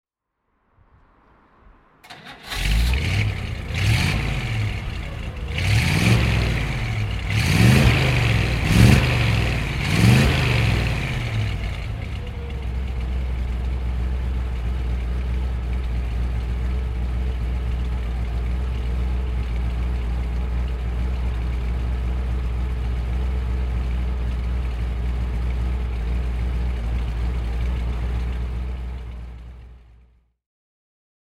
Chevrolet Bel Air (1956) - starten und Leerlauf
Chevrolet_Bel_Air_1956.mp3